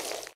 tbd-station-14/Resources/Audio/Effects/Footsteps/snake3.ogg at 0bbe335a3aec216e55e901b9d043de8b0d0c4db1
snake3.ogg